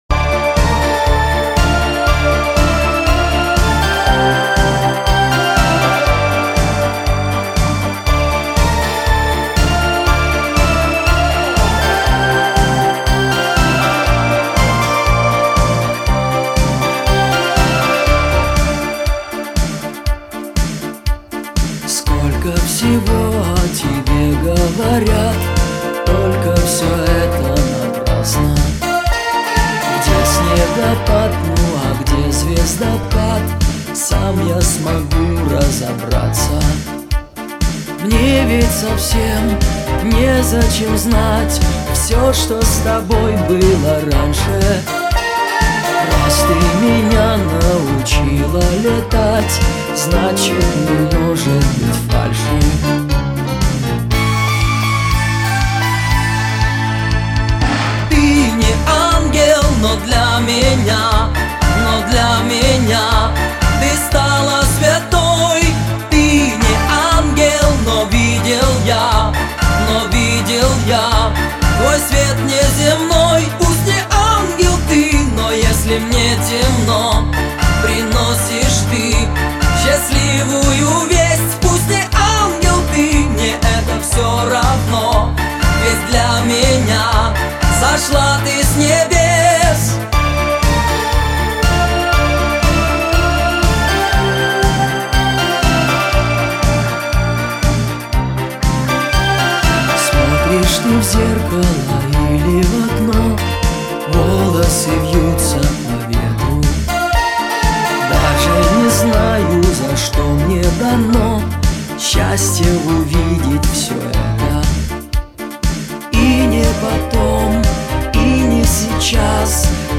яркая поп-рок композиция